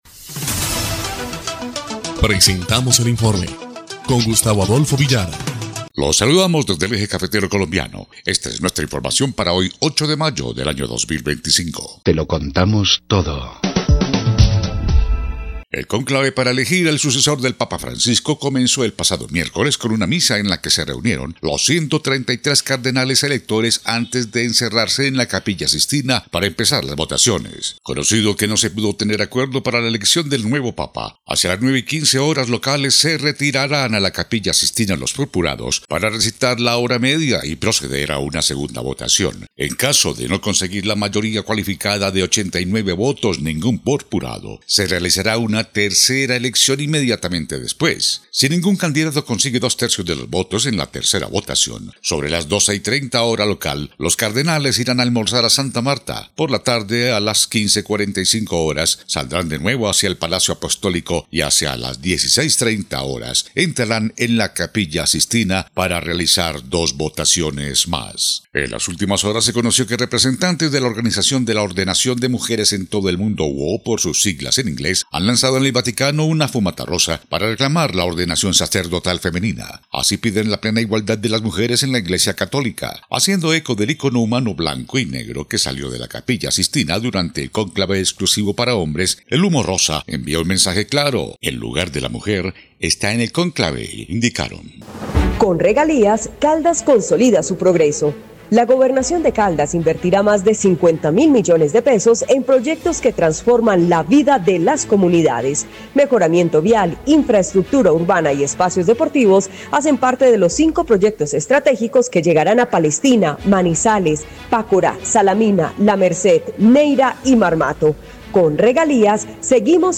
EL INFORME 2° Clip de Noticias del 8 de mayo de 2025